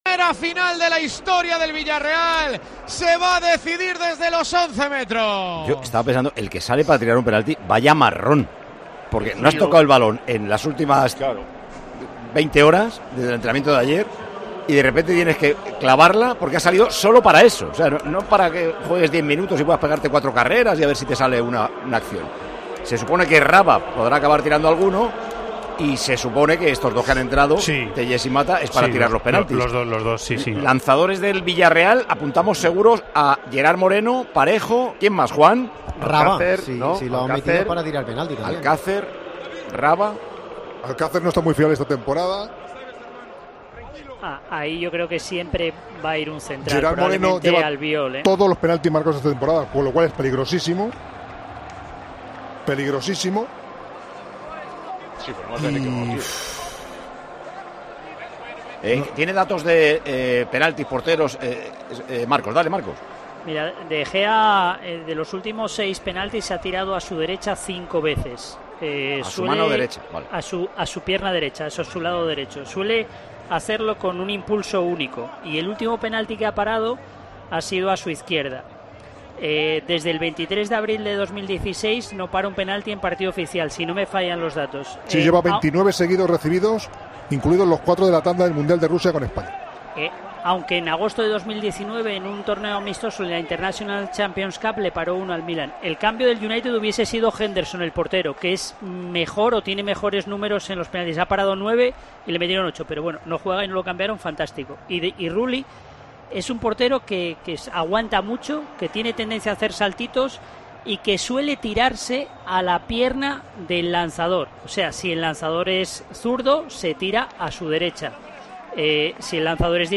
Así narramos en Tiempo de Juego la histórica tanda de penaltis que convirtió en campeón al Villarreal